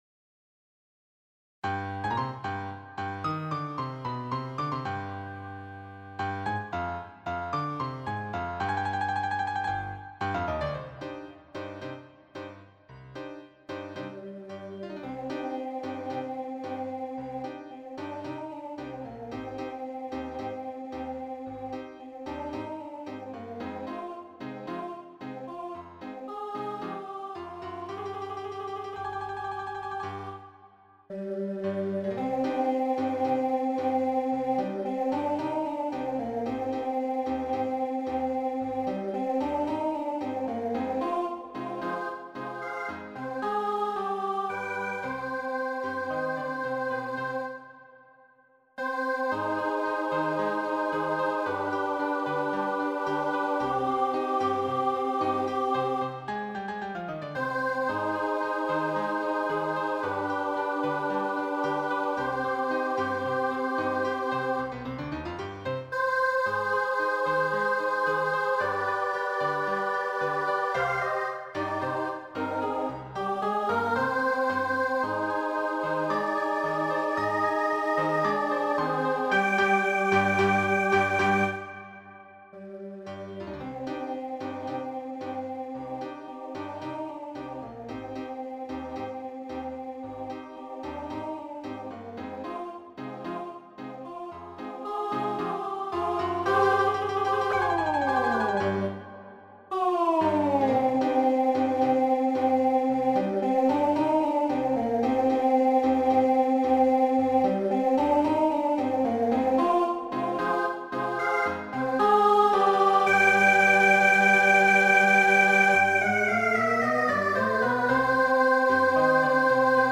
SSA with piano
MIDI demo